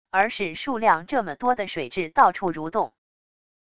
而是数量这么多的水蛭到处蠕动wav音频生成系统WAV Audio Player